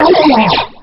Line of Bubbler in Diddy Kong Racing.